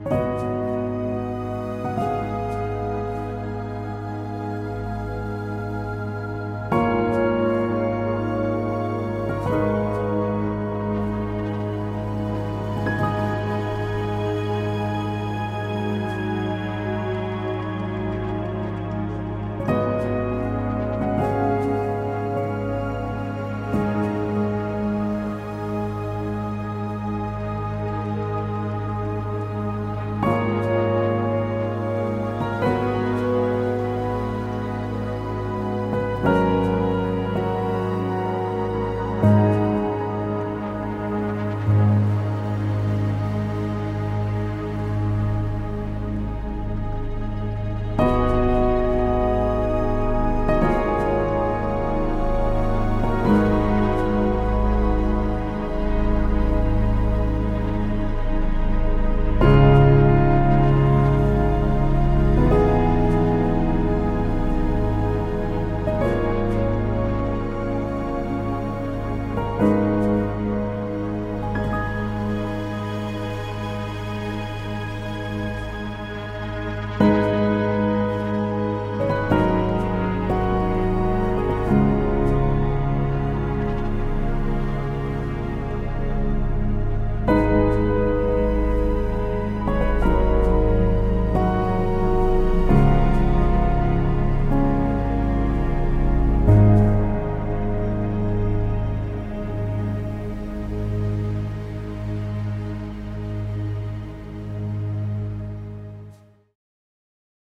通过使用子、近、中、远四个精美捕捉的麦克风视角，可以实现出色的混音，展现出令人难以置信的音调和木材。
通过卓越的背景音乐、令人惊叹的混合键盘、复杂而微妙的节奏以及丰富的钢琴质感，找到灵感并开始编写下一个故事。